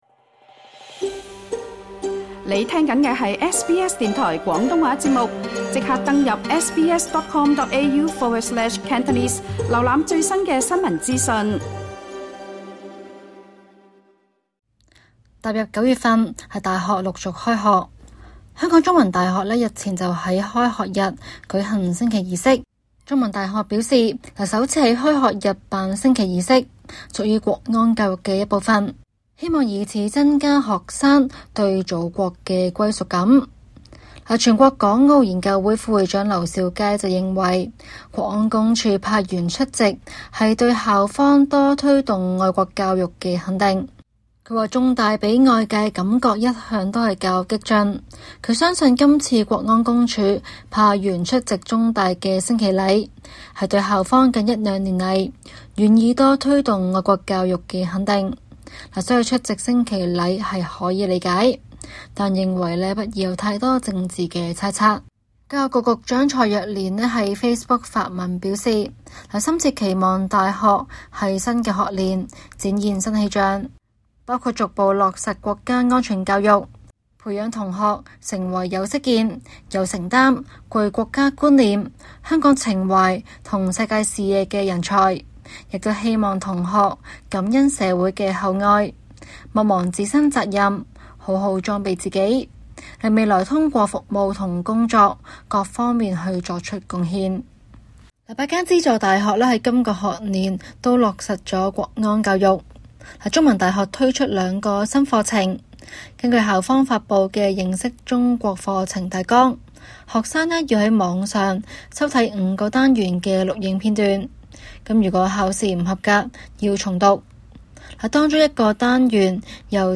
SBS廣東話節目 View Podcast Series Follow and Subscribe Apple Podcasts YouTube Spotify Download (4.94MB) Download the SBS Audio app Available on iOS and Android 中大日前在開學日首次舉行升旗儀式，落實國安教育，具體的情況如何？